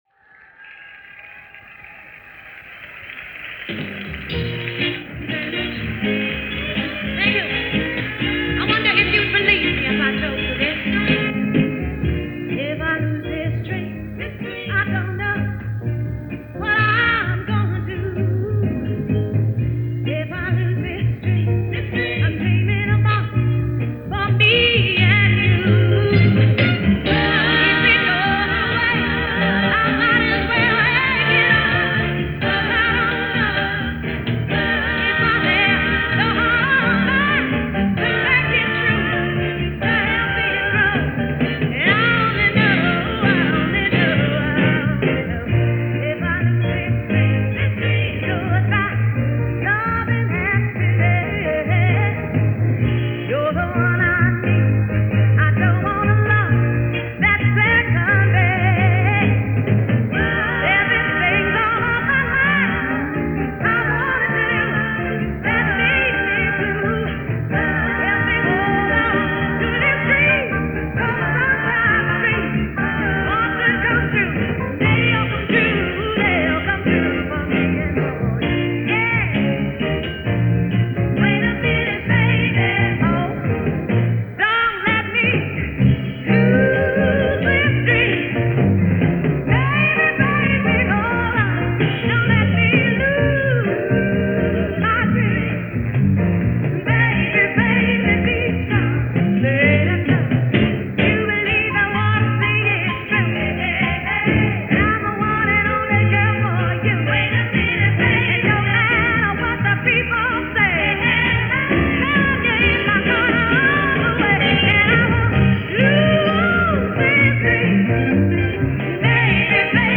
in concert from Stockholm, Sweden